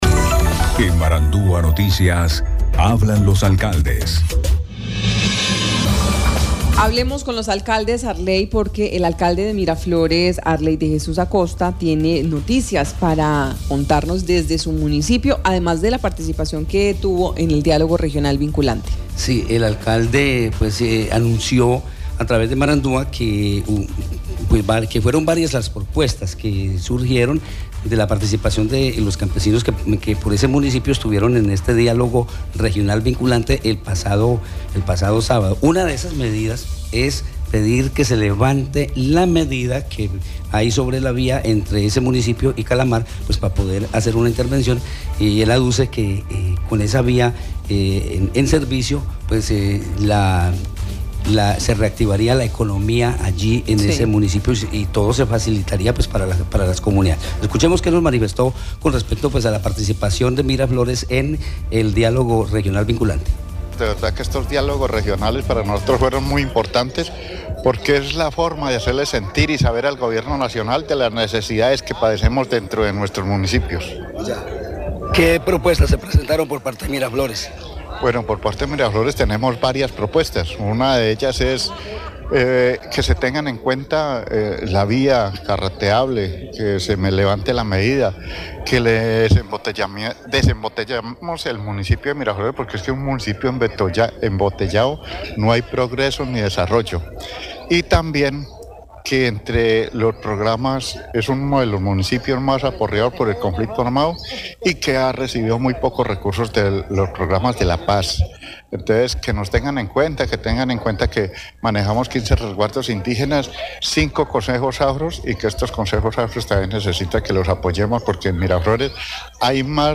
Alcalde de Miraflores, Arley de Jesús Acosta, anunció en Marandua Noticias que fueron varias las propuestas presentadas en el Diálogo Regional Vinculante, entre ellas que se levante la medida para intervenir la vía a Calamar.